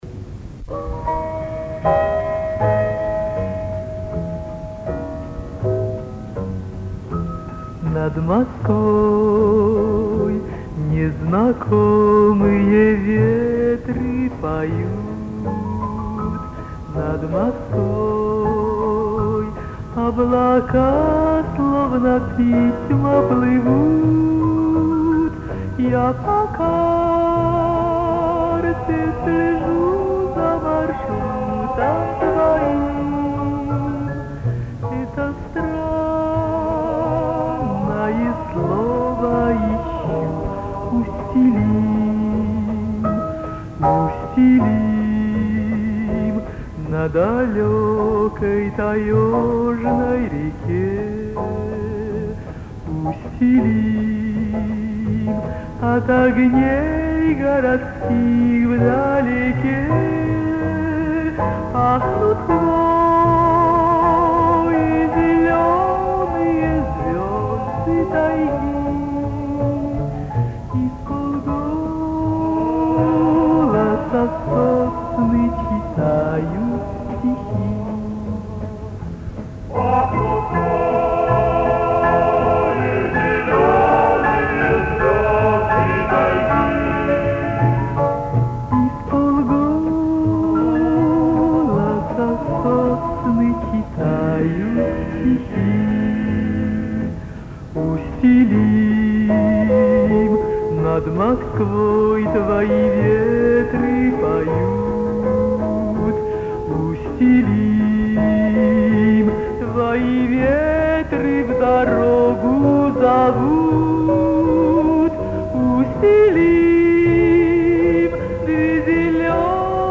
Источник аудиокассета